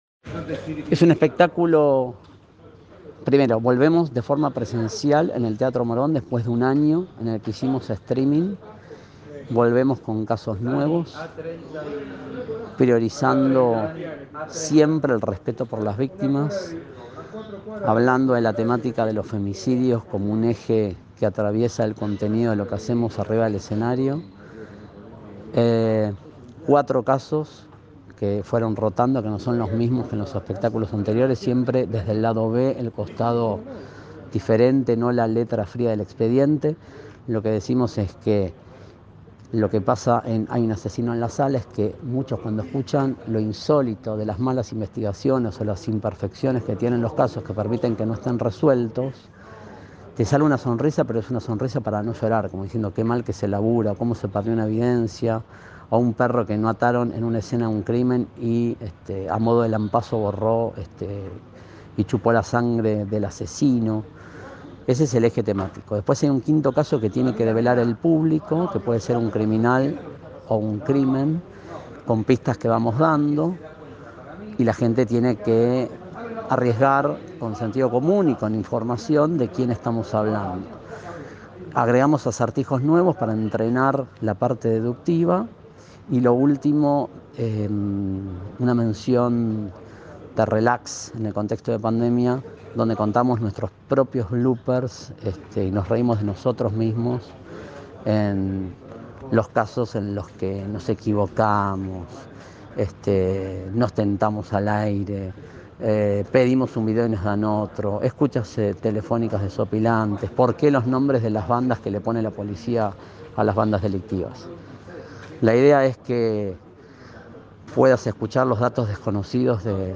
“Volvemos con casos nuevos”, destacó Szeta en conversación con Primer Plano Online.
AUDIO: MAURO SZETA CUENTA DETALLES DEL ESPECTÁCULO